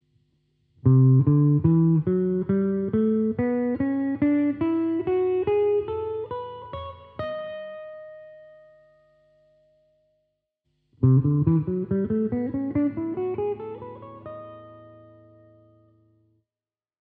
16 note jazz scale using tetrachords
TETRACHORD SCALE